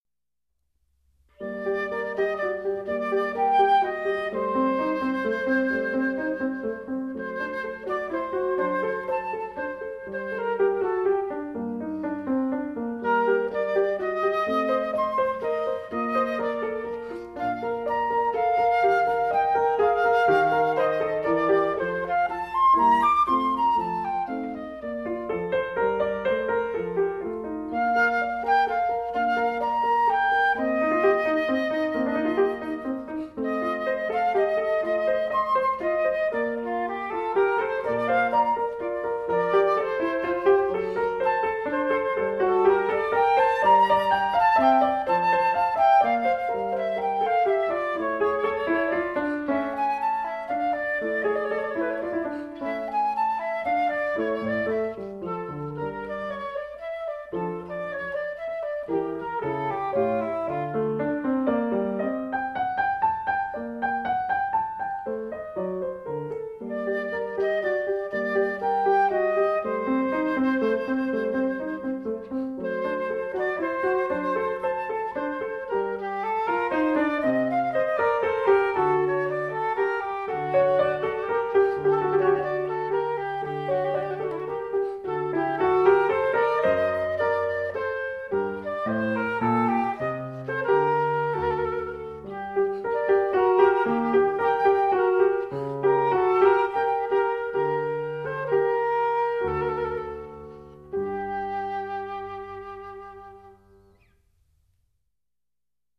小提琴合奏、钢琴的协奏的音色是如此地柔美和靓丽！